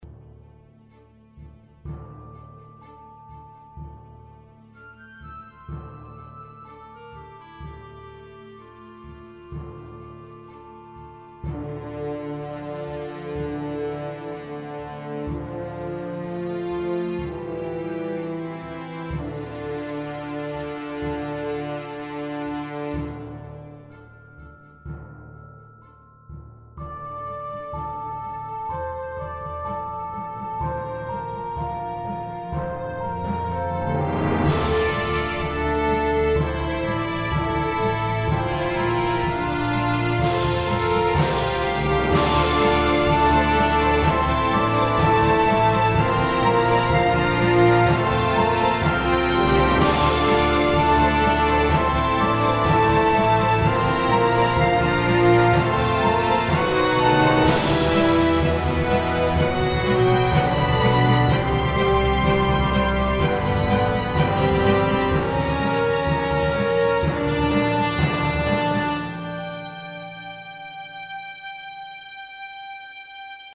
Angelic